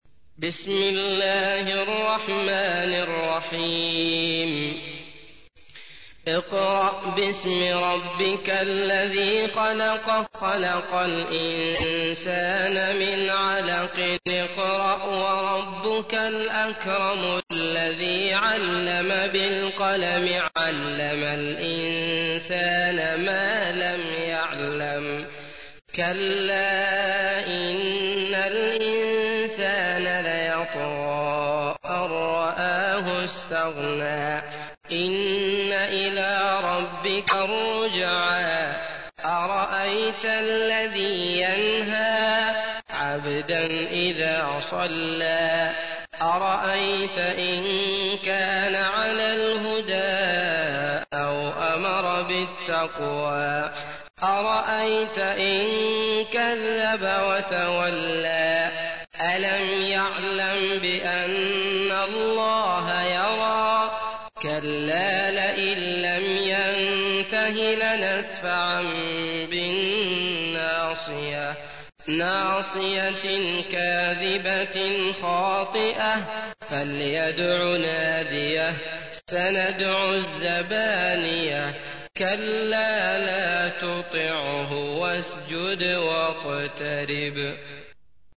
تحميل : 96. سورة العلق / القارئ عبد الله المطرود / القرآن الكريم / موقع يا حسين